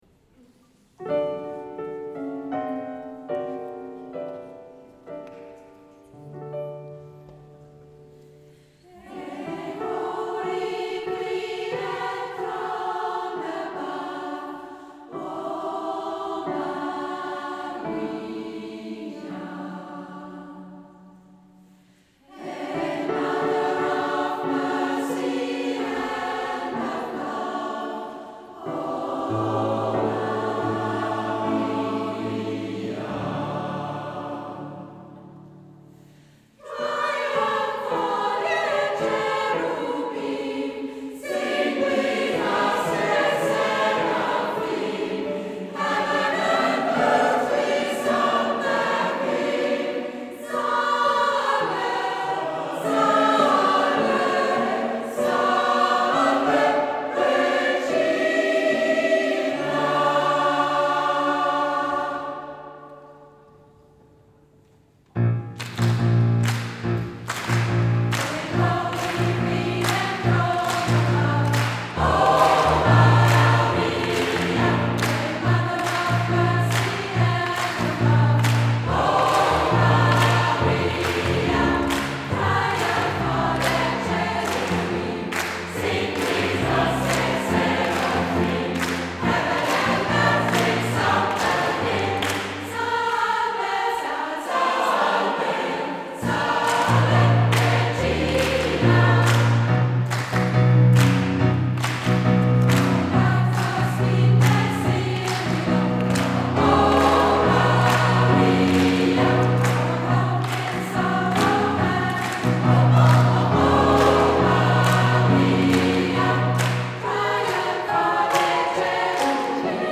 Komm in unseren gemischten Chor schnuppern und finde selbst heraus, ob es dir bei «the waves» in Goldach gefällt.
Oder höre die Kostproben von unserem Konzert in St. Gallen am 9. November 2024 (zusammen mit „Queerbeat Gaiserwald“).